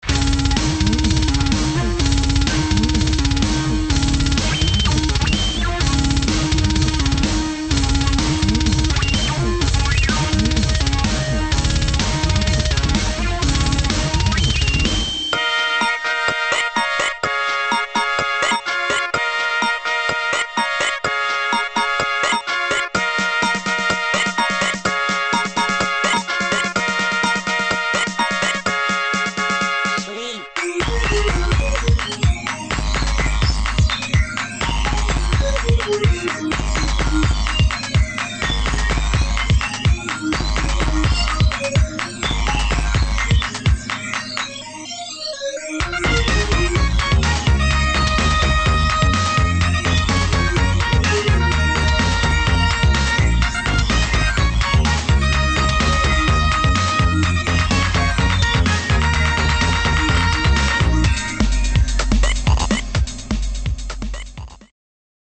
[ HOUSE | TECHNO | ELECTRO ]